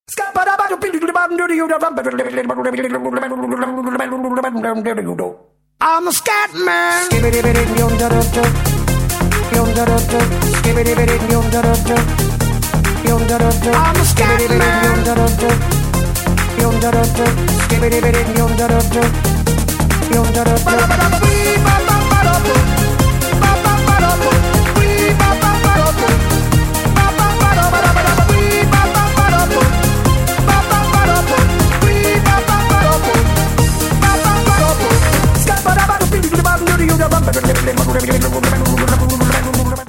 • Качество: 192, Stereo
зажигательные
веселые
Eurodance
ретро